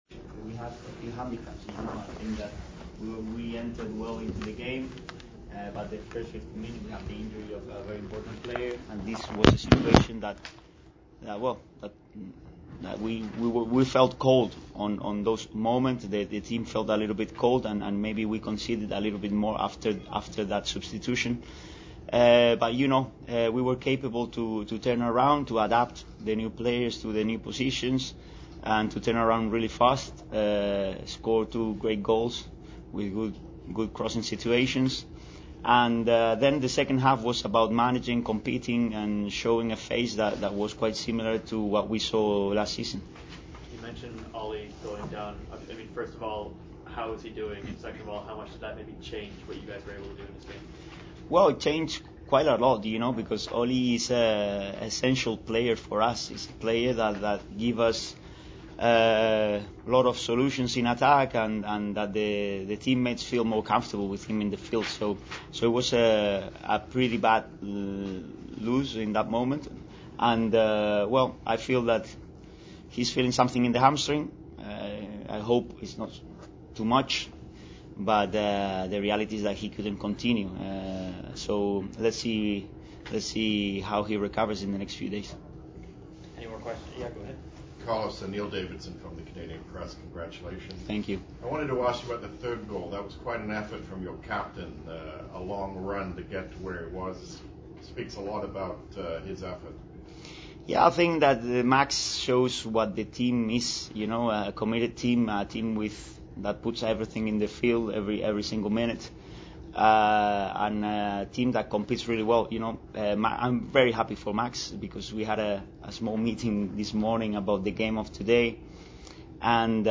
April 19, 2023...post game press conference after the HFX Wanderers FC vs Atletico Ottawa Canadian Championship game
The next three questions and answers are in French.